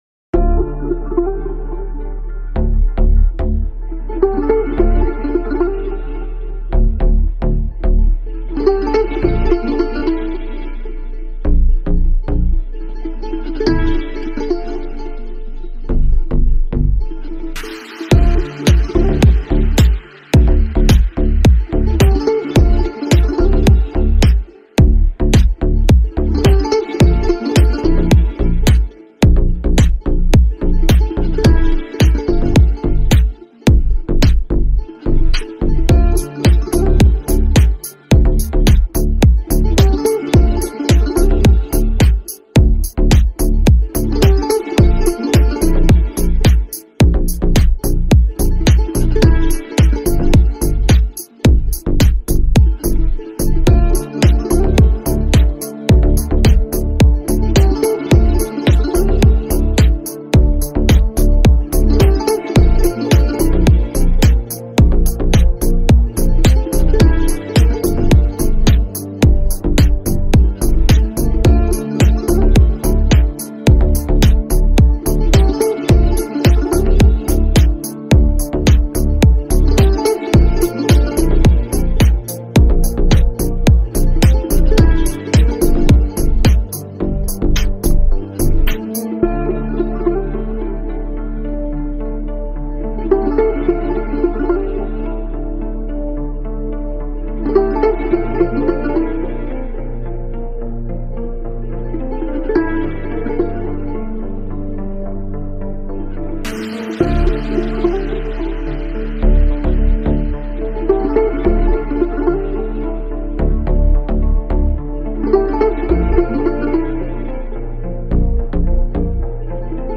глубокое и эмоциональное произведение в жанре поп-рок